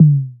Waka Kick 2 (10).wav